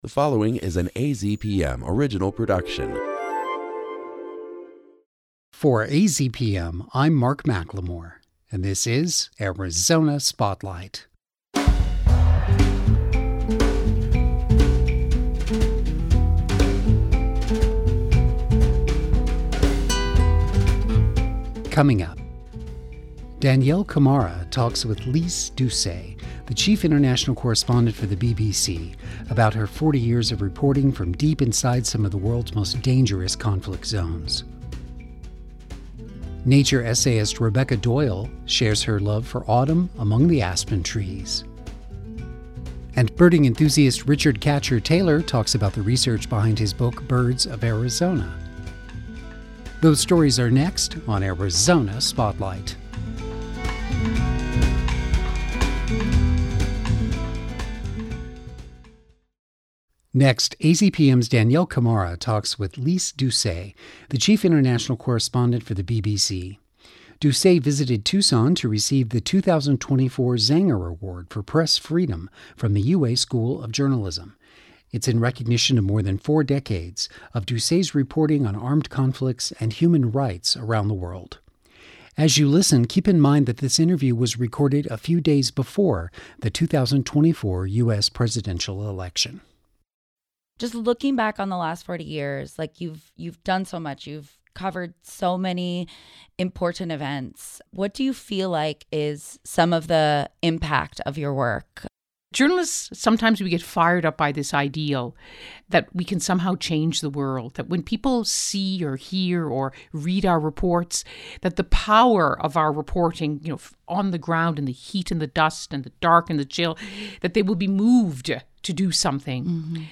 We'll take you behind the scenes of the annual “LightsUp!” festival of illumination at Tucson Botanical Gardens. The documentary "Growing Tradition" provides details about this dazzling display that requires more than a million sparkling lights and other decorations.